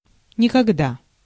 Ääntäminen
US : IPA : /ˈnɛvɚ/ UK UK : IPA : /ˈnɛv.ə(ɹ)/